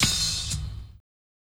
HIHAT_OPEN_APRIL.wav